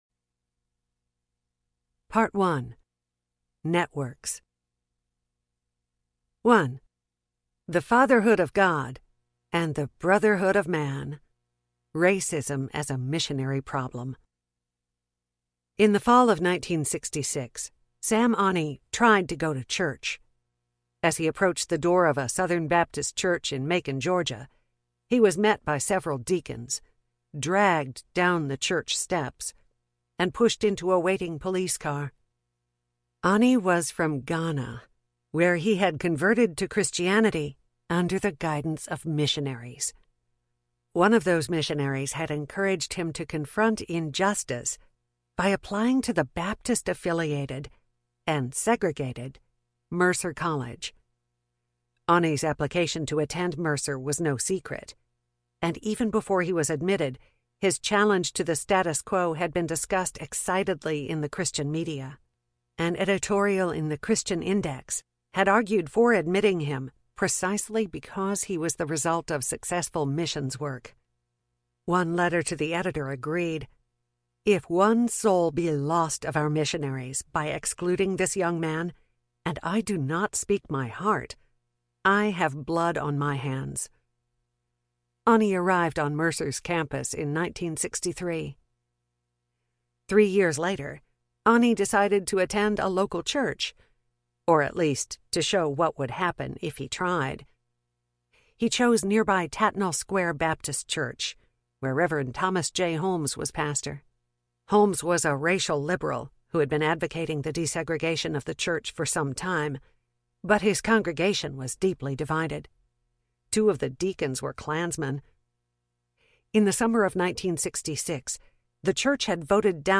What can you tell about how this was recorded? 17.3 Hrs. – Unabridged